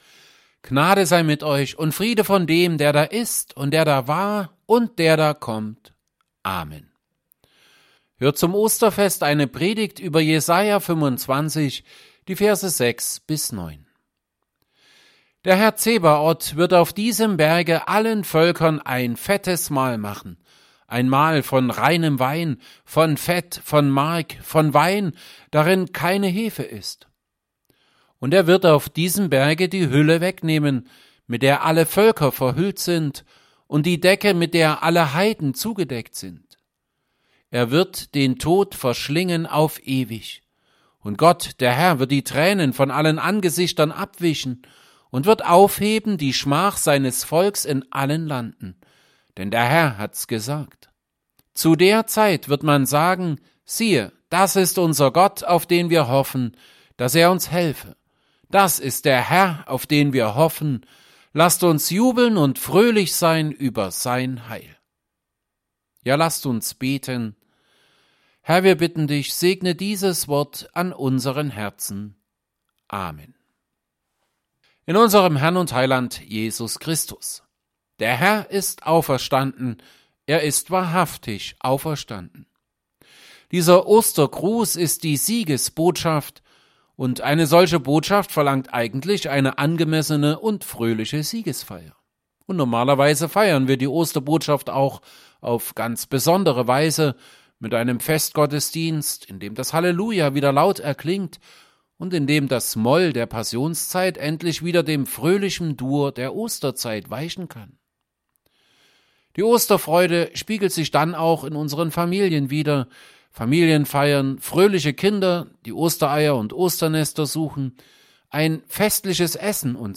Predigt_zu_Jesaja_25_6b9.mp3